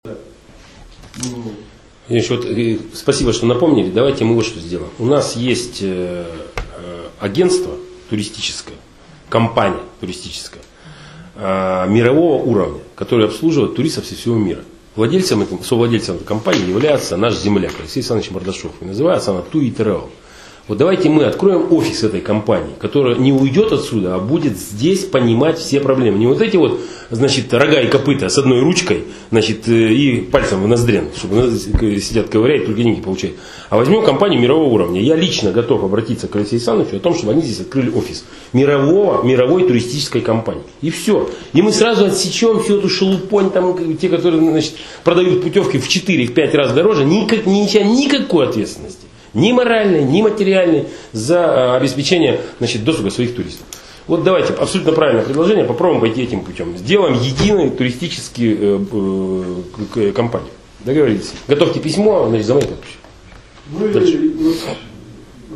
Губернатор побывал в Великом Устюге
Напомним, в пятницу, 31 августа, губернатор Вологодской области побывал с рабочим визитом в Великоустюгском районе.